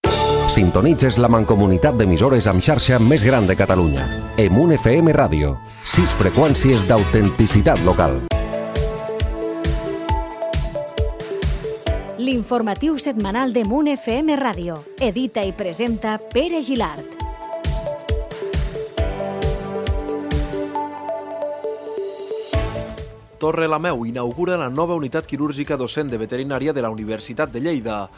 Indicatiu i entrada de l'informatiu setmanal: Inaugurada la Unitat Quirúrgica Docent de Veterinària de la Universitat de Lleida a Torrelameu.
Informatiu